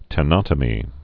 (tĕ-nŏtə-mē)